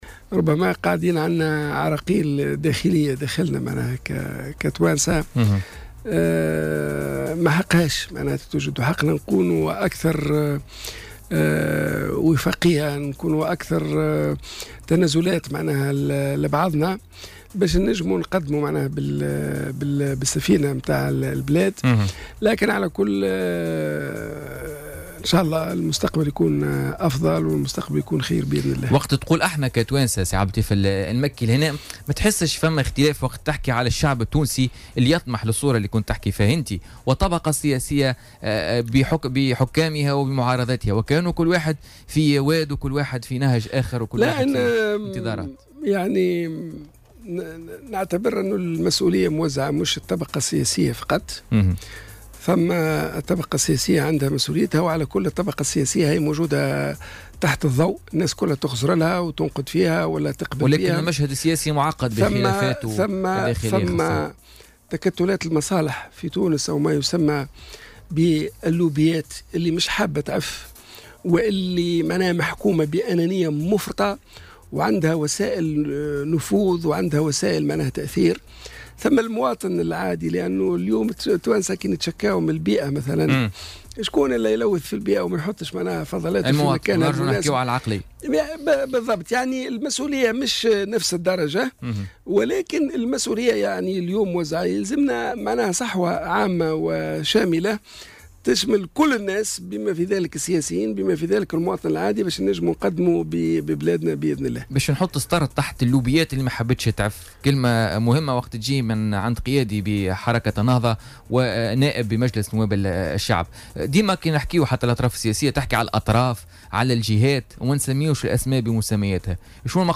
وقال ضيف "بوليتيكا" في استديو "الجوهرة اف أم" بتونس العاصمة إنه هناك تكتلات مصالح محكومة بأنانية مفرطة ولديها وسائل نفوذ وتأثير، مردفا بالقول بالدارجة التونسية: "اللوبيات ما حبتش تعف".